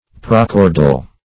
prochordal - definition of prochordal - synonyms, pronunciation, spelling from Free Dictionary
Search Result for " prochordal" : The Collaborative International Dictionary of English v.0.48: Prochordal \Pro*chor"dal\, a. [Pref. pro + chordal.]